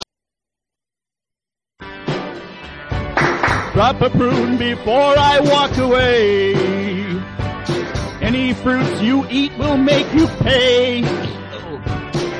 Rock and Roll classics